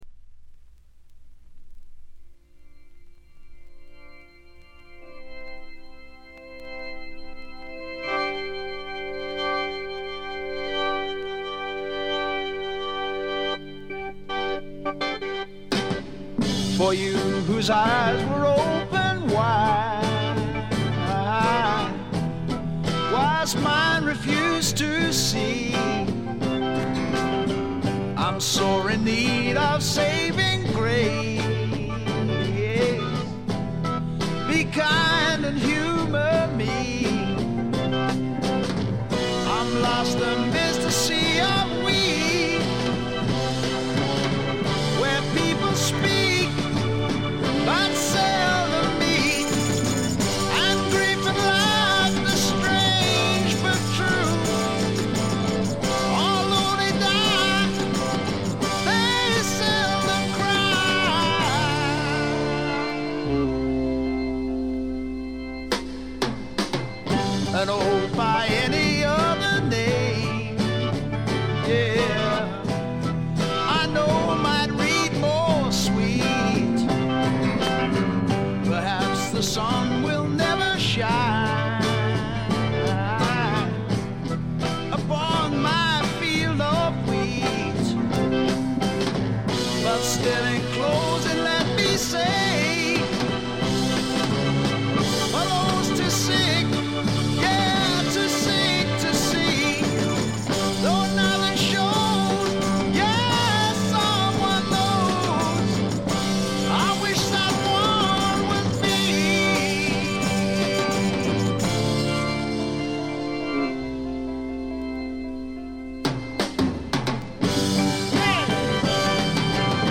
見た目よりやや劣る感じで、静音部でバックグラウンドノイズ、軽微なチリプチ。大きなノイズはありません。
試聴曲は現品からの取り込み音源です。